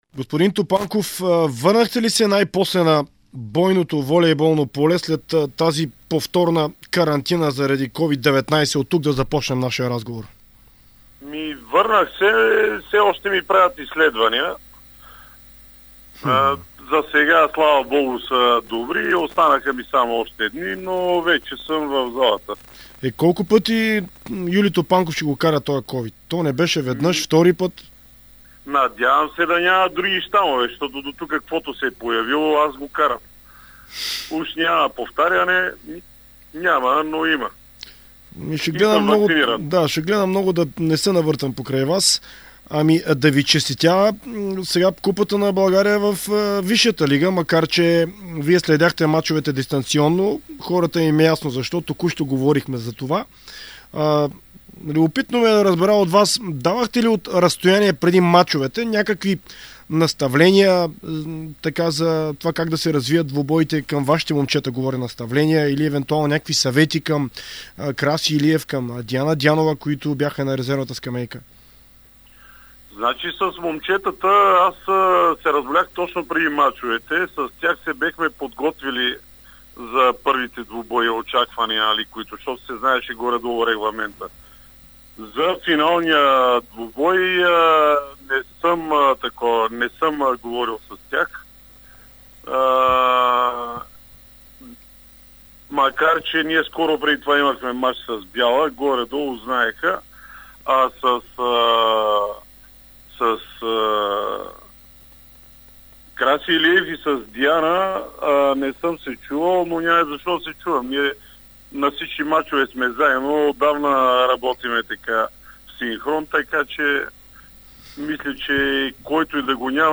В същото време той заяви в интервю за Дарик радио и dsport, че вариантът с турнир за купата на България в Суперлигата и такъв във висшата лига е логичен и добър.